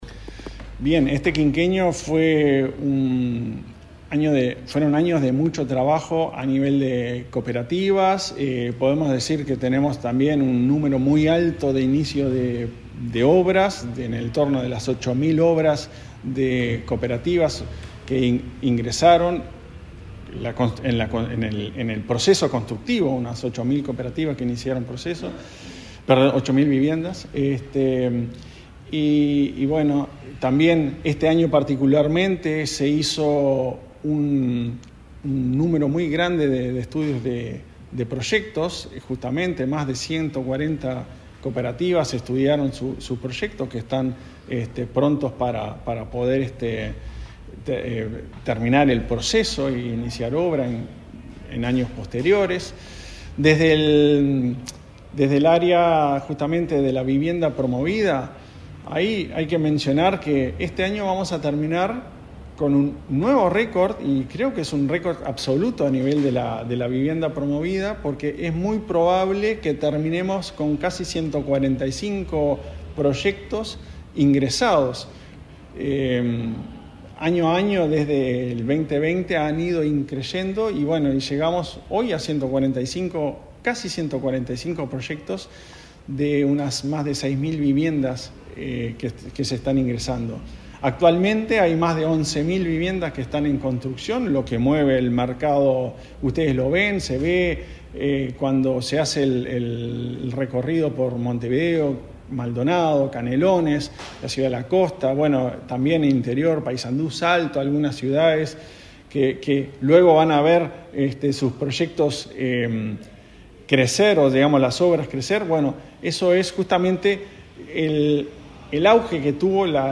Entrevista al presidente de la ANV, Klaus Mil